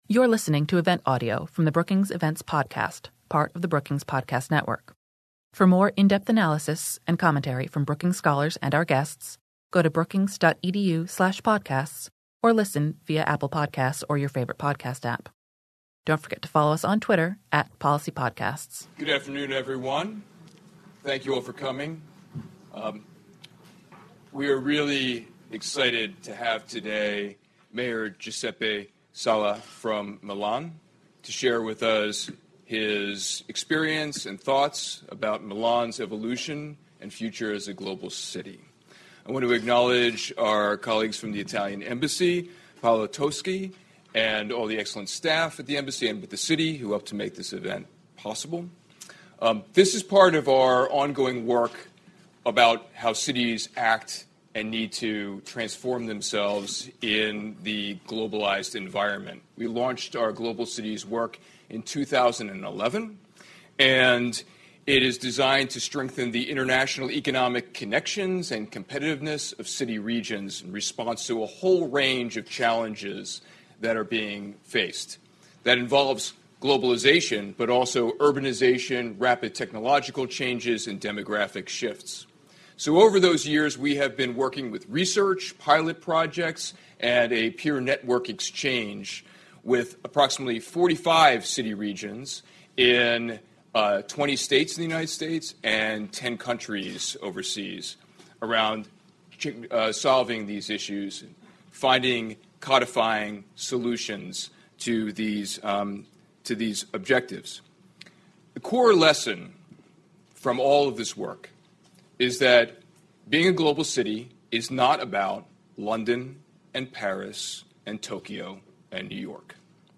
On Tuesday, May 14, the Metropolitan Policy Program at Brookings hosted a conversation with Mayor Giuseppe Sala to discuss Milan’s global identity and competitiveness, and lessons for other mid-size city-regions around the world.
In a keynote, the mayor reviewed the Milan 2030 Plan to further enhance its global position through inclusion, sustainability, and connection.